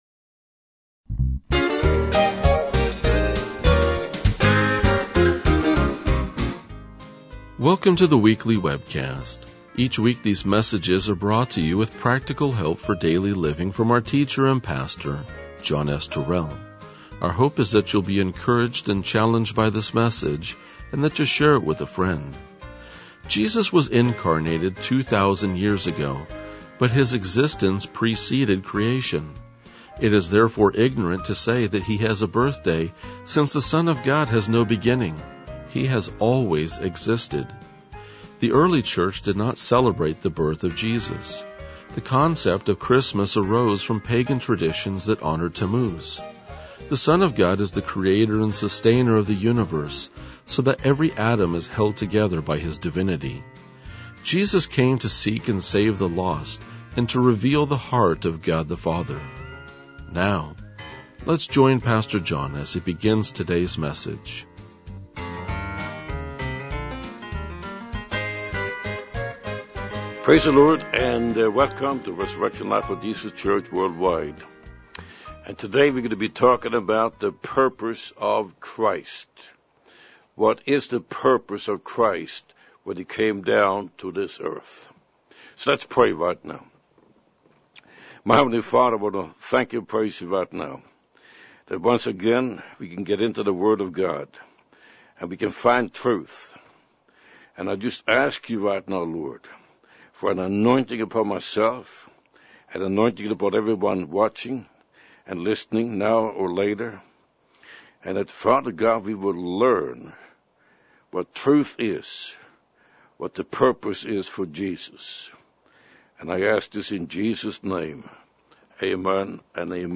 RLJ-2037-Sermon.mp3